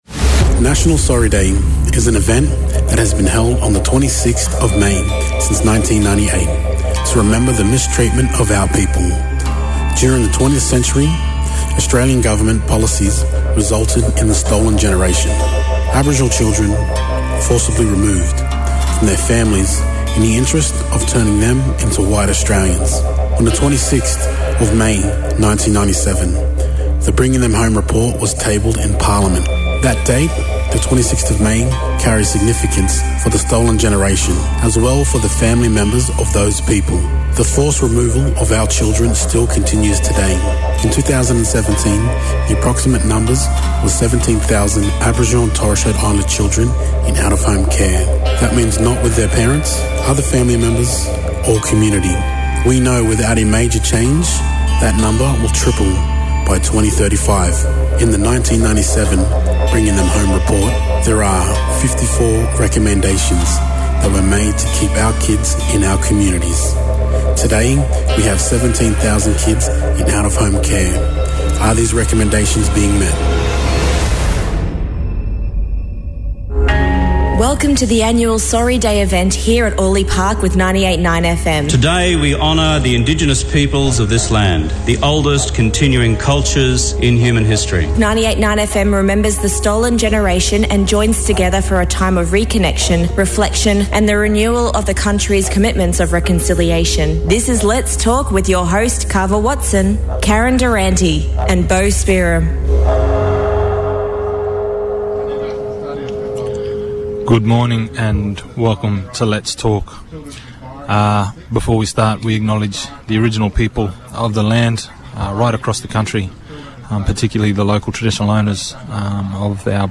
Today on Let’s Talk we had a special broadcast from Cranebrook Place in Orleigh Park, Link-up QLD organised to commemorate National Sorry Day.